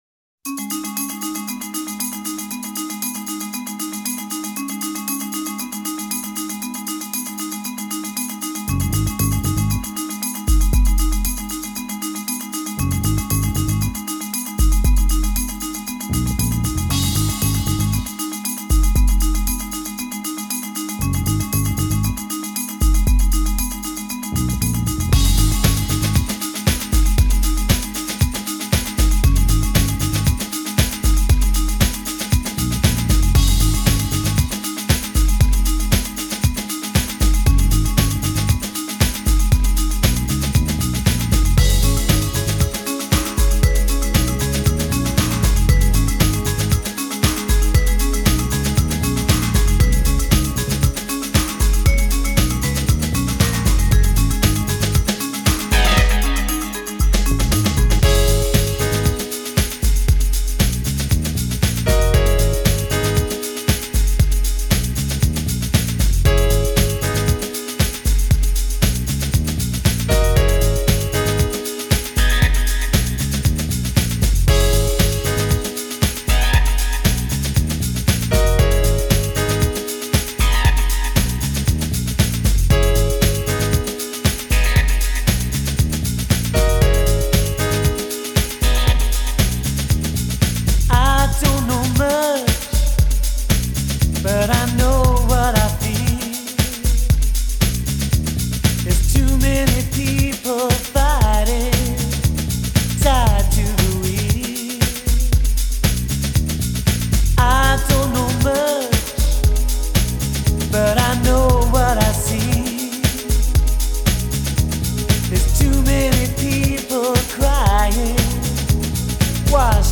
Sunday morning’s bleary-eyed groove
a silken husk that duets with itself throughout the record